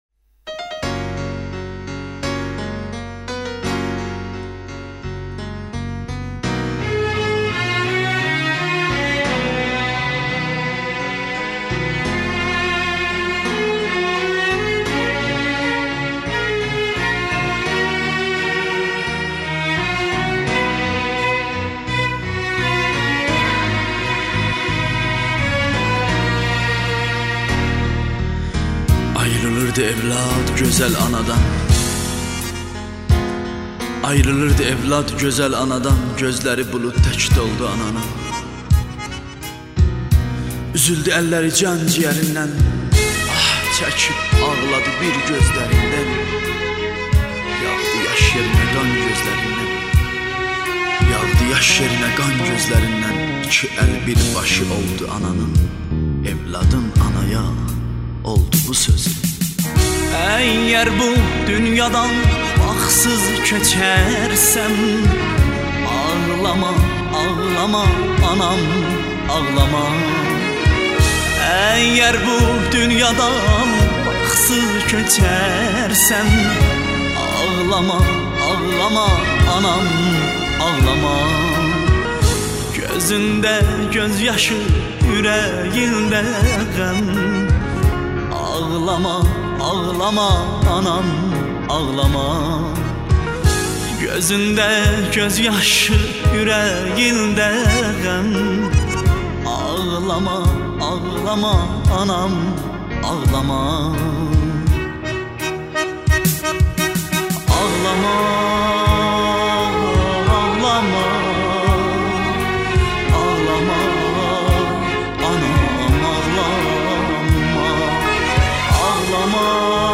موسیقی آذری